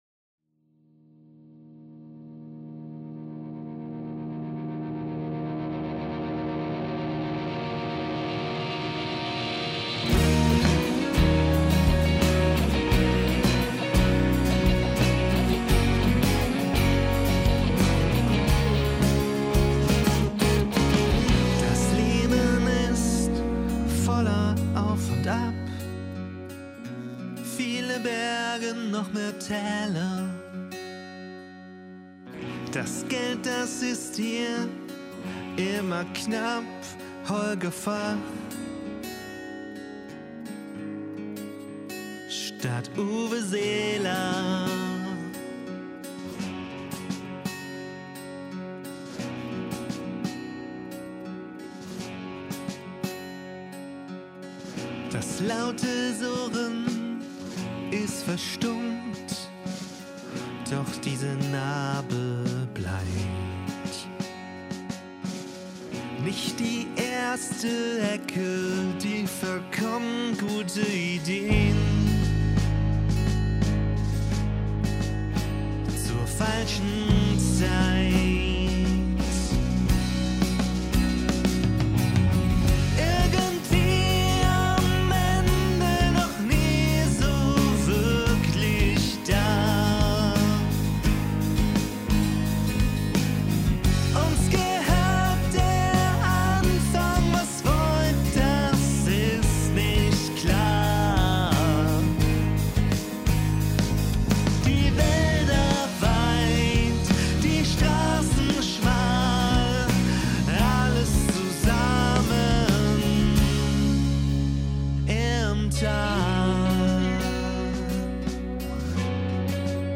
Deutschsprachiger Pop-Rock.